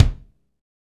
Index of /90_sSampleCDs/Northstar - Drumscapes Roland/KIK_Kicks/KIK_H_H Kicks x
KIK H H K03R.wav